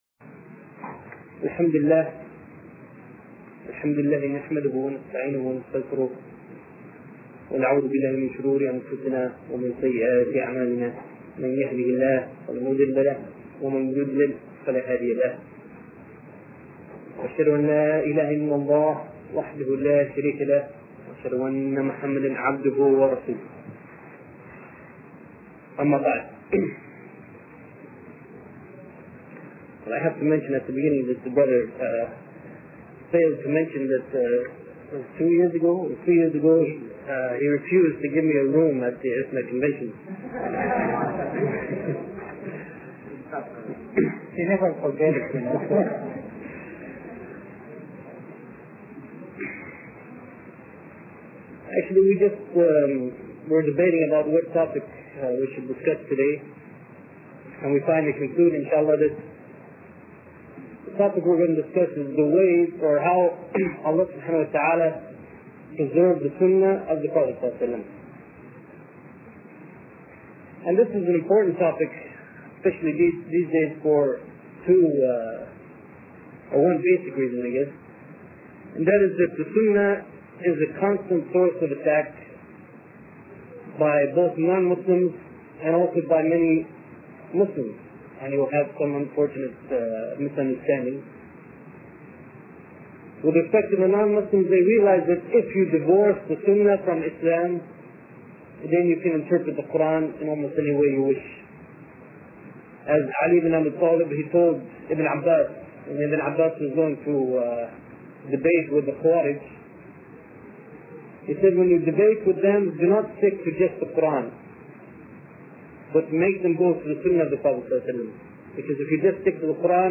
A lecture in English